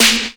kits/RZA/Snares/WTC_SNR (51).wav at 32ed3054e8f0d31248a29e788f53465e3ccbe498